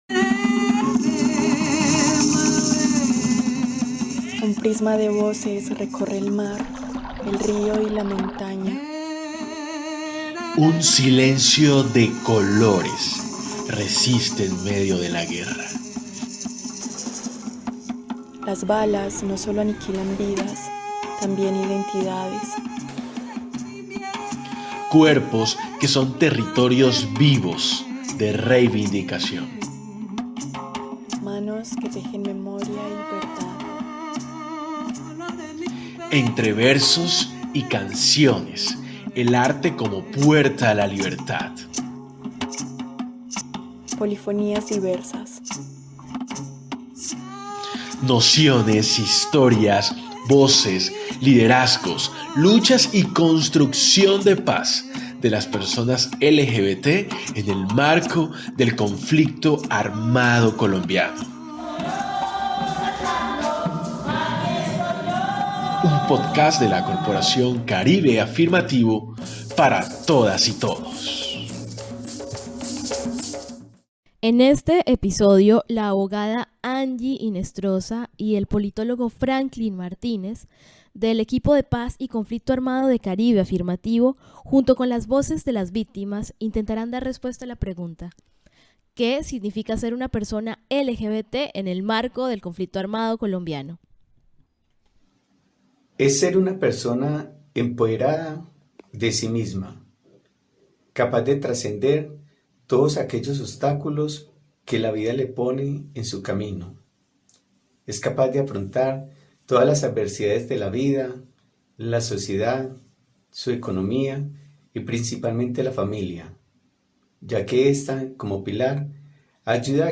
?> escuchar audio TIPO DE DOCUMENTO Entrevistas ALCANCE Y CONTENIDO Polifonías Diversas es un podcast producido por la corporación Caribe Afirmativo.